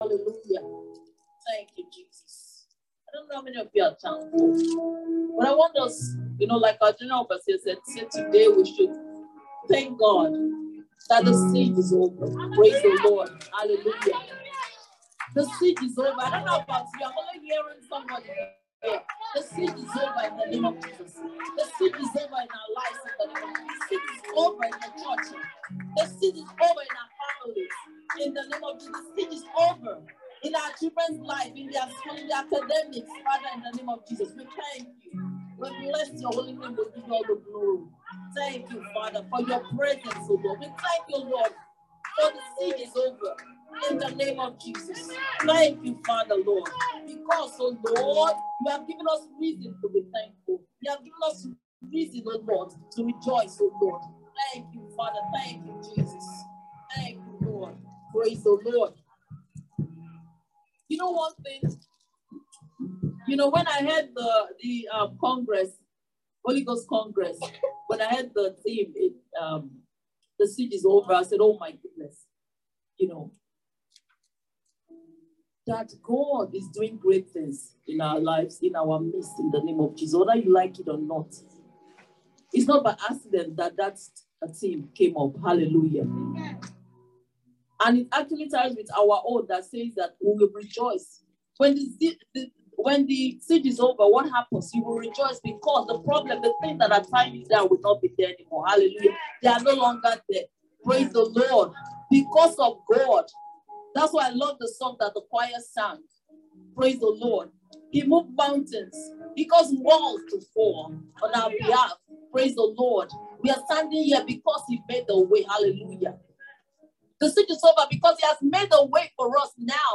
Listen to the Chapel of the Great Restorer sermon from December 12, 2021, titled I Shall Rejoice.